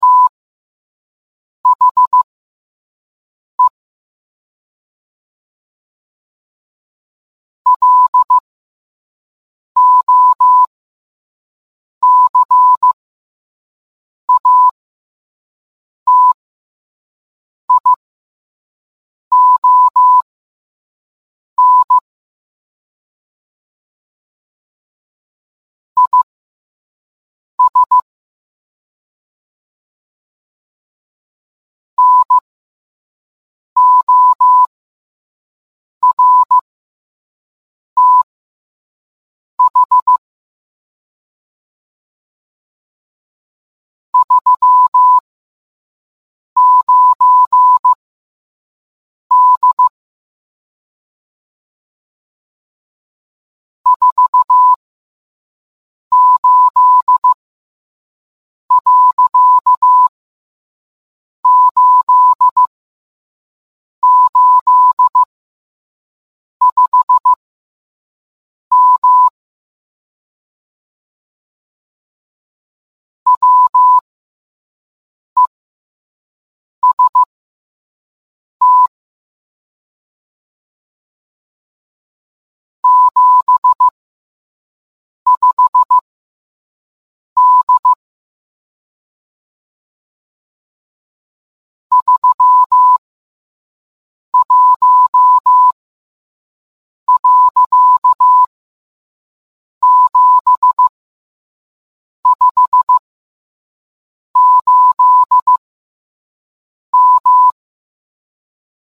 Radio Message
Radio_Message.mp3